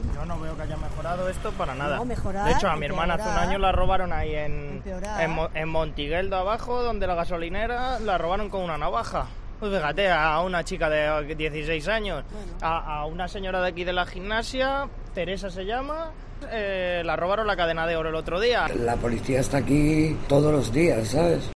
Hablamos con los vecinos de Vallecas sobre los últimos robos en el barrio